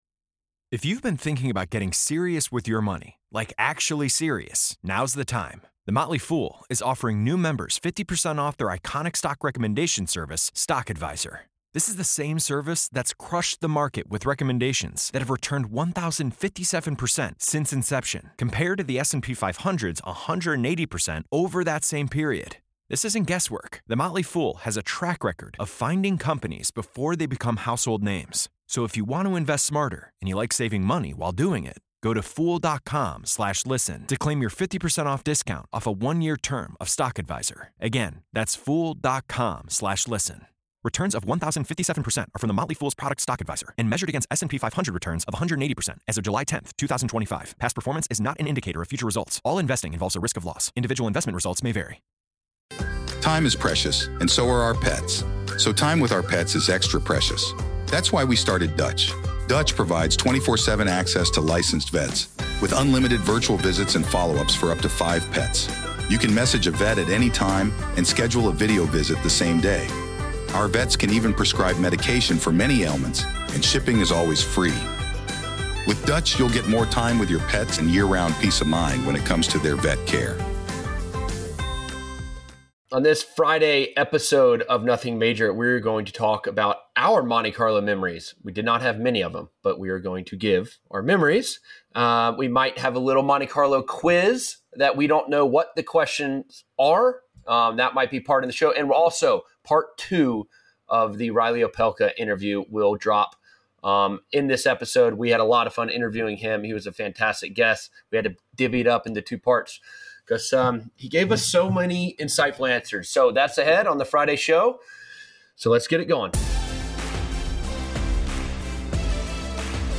The episode wraps with the Part 2 of Reilly Opelka interview.